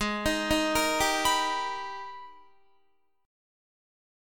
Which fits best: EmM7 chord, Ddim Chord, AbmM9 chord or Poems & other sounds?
AbmM9 chord